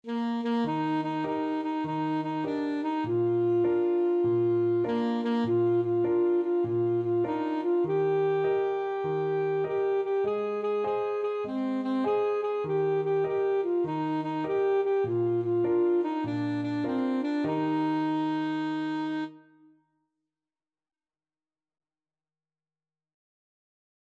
Alto Saxophone
4/4 (View more 4/4 Music)
With a swing!